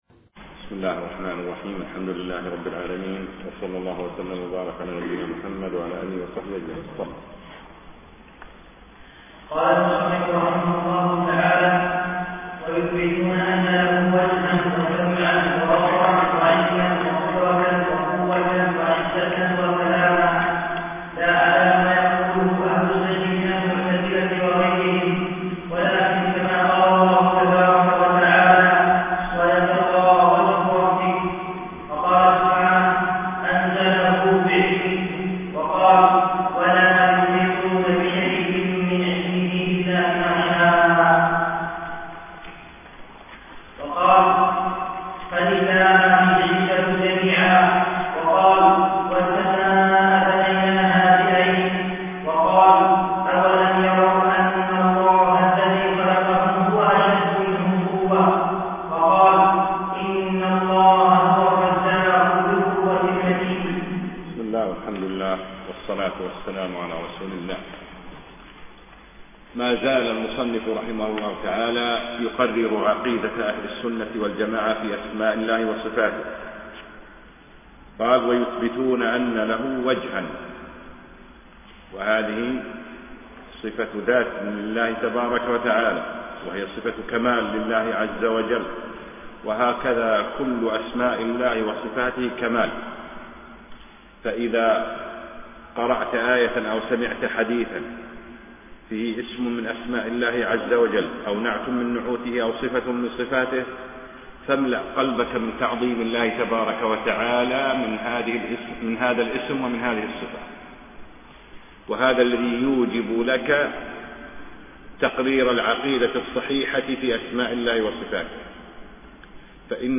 أقيمت الدورة في دولة الإمارات
الدرس الثالث